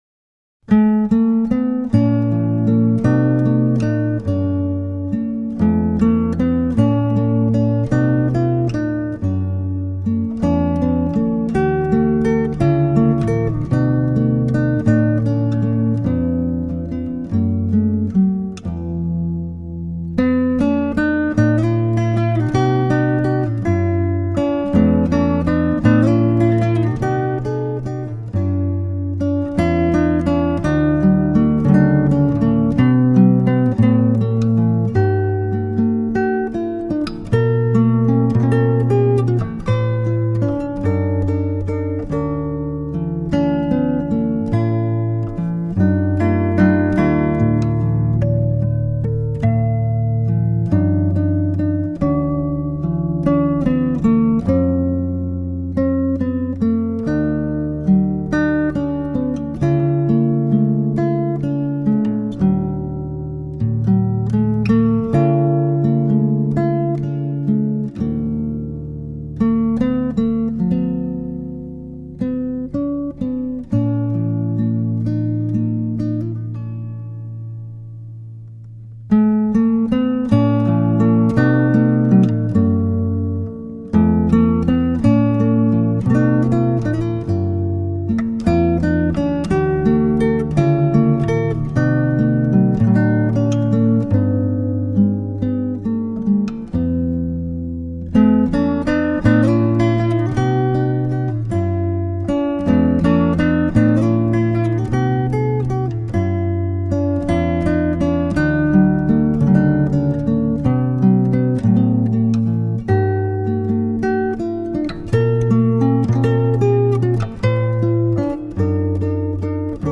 موسیقی کنار تو
آرامش بخش گیتار موسیقی بی کلام
گیتار آرامبخش